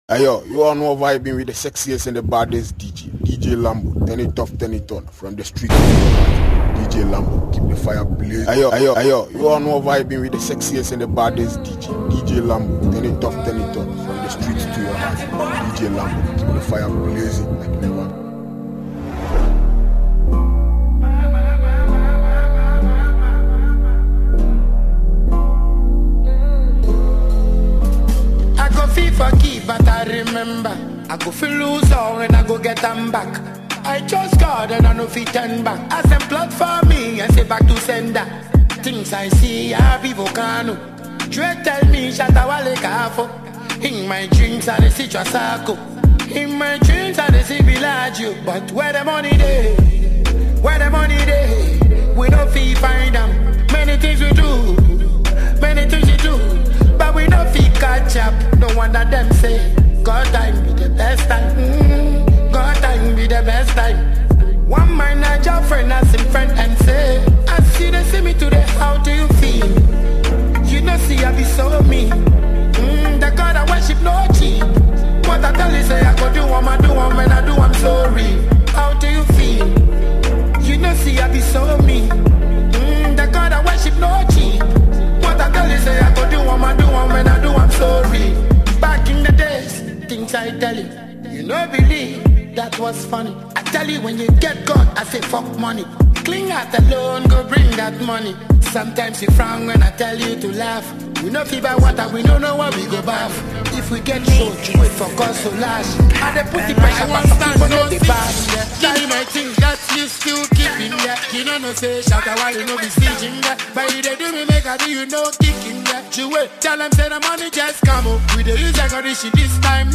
mix
Genre: Mixtape